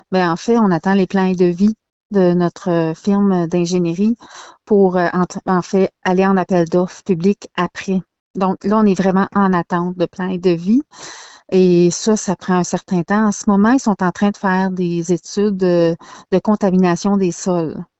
La mairesse de Saint-Célestin Paroisse, Sandra St-Amour, a assuré que le dossier de réparation du glissement de terrain continue de progresser.